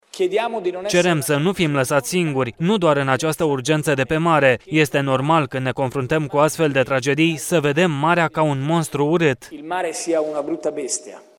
voce-renzi.mp3